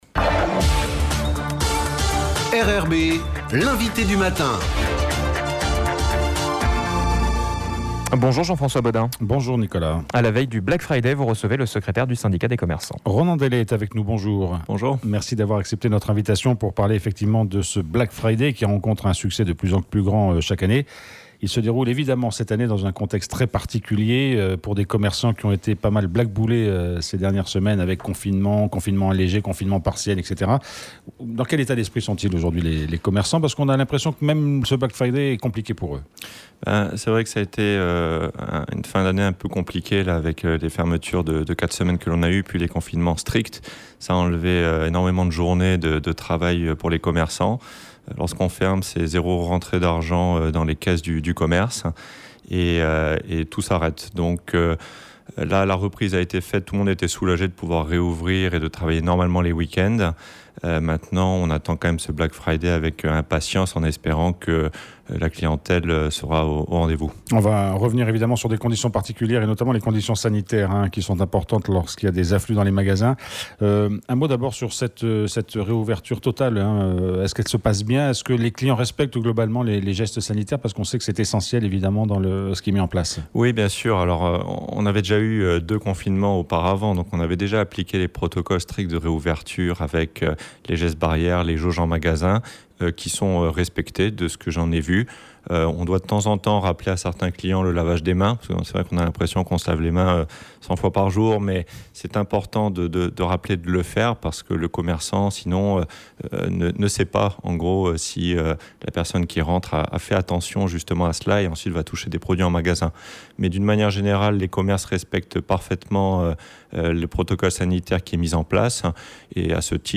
L'INVITE DU MATIN